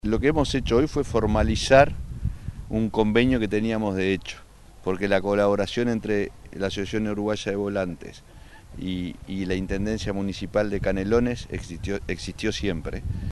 Se realizó la firma de convenio entre la Intendencia de Canelones y la Asociación Uruguaya de Volantes (AUVO) en el Autódromo Víctor Borrat Fabini de El Pinar en Ciudad de la Costa, cuyo objetivo es permitir el desarrollo de las obras de mantenimiento y ampliación del autódromo y las áreas circundantes.
julio_maglione_presidente_de_auvo.mp3